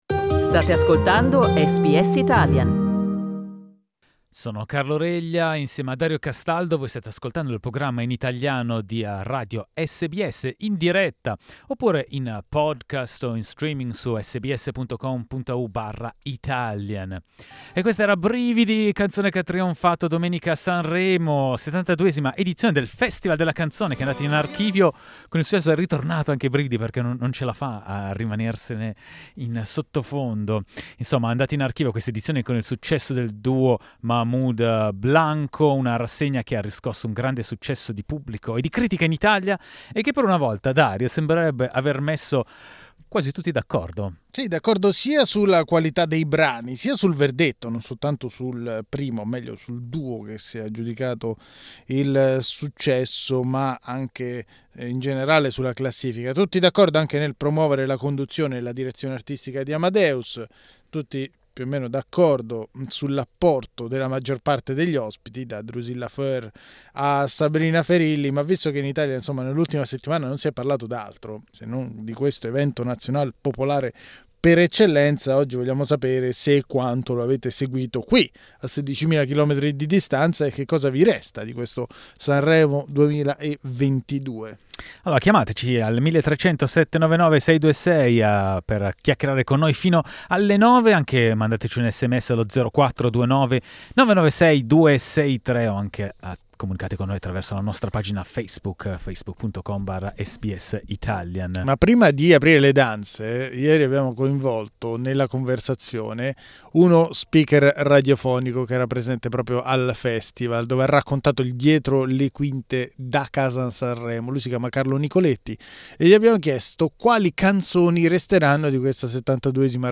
La 72ma edizione del festival della canzone italiana è stata un successo, sia per il pubblico che per la critica. Ascoltatori e ascoltatrici sono intervenuti in diretta per raccontarci cosa hanno apprezzato di più e cosa invece, a loro parere, poteva essere evitato.